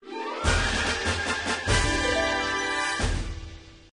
UI_complete.ogg